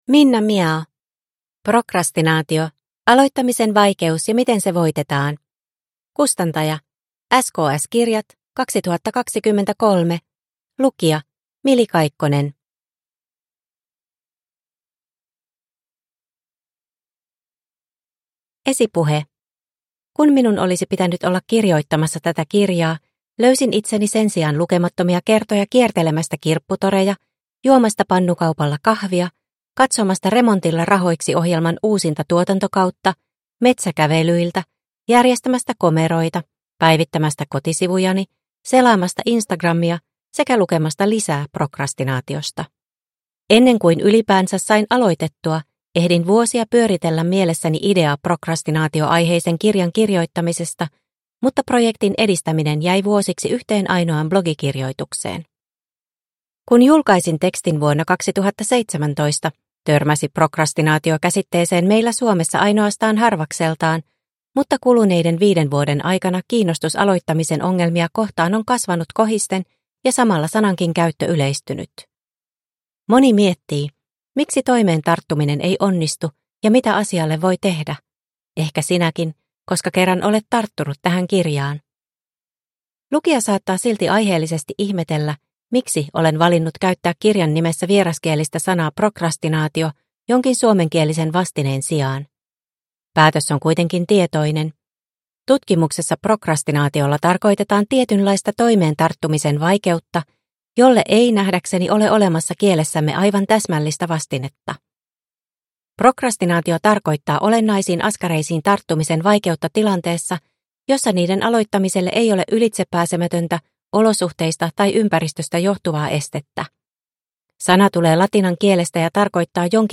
Prokrastinaatio – Ljudbok – Laddas ner